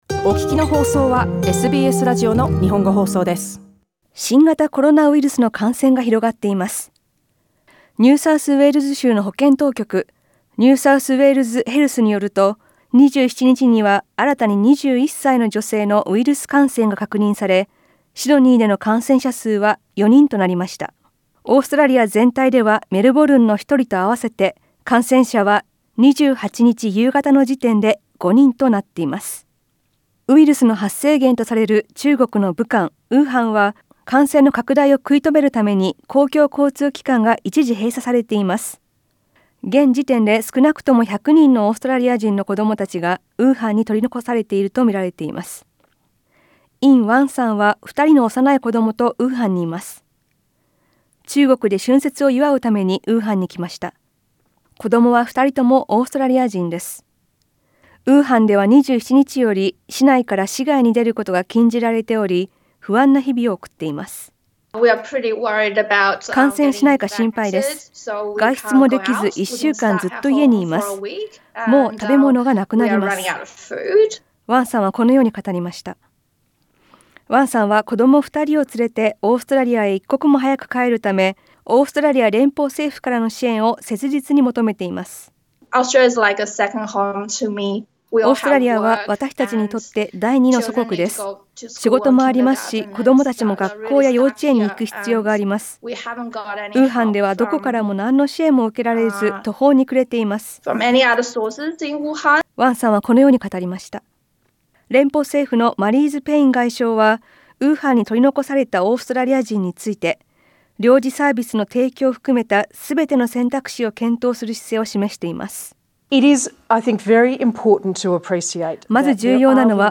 新学期を控え、最近中国を訪れた生徒などに自宅待機を呼び掛ける動きが出てきました。2020年１月28日放送。